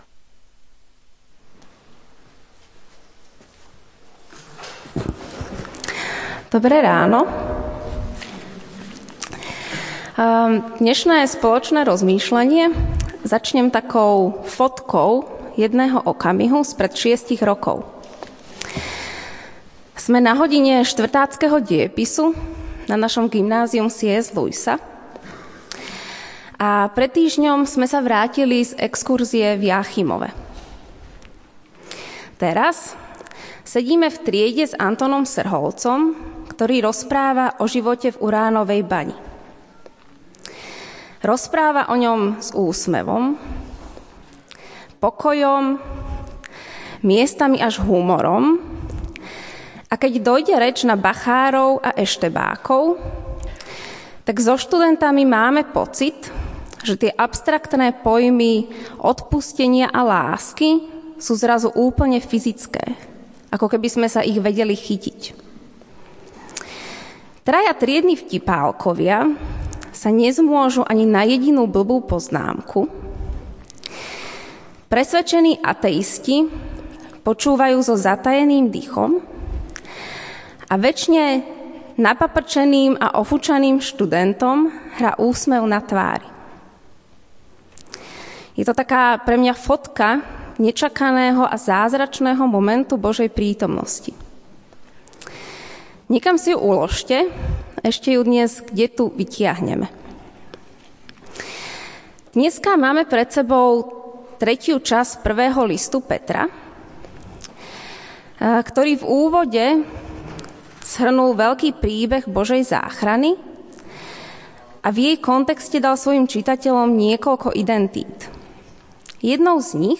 Stránka zboru Cirkvi bratskej v Bratislave - Cukrová 4
Kázeň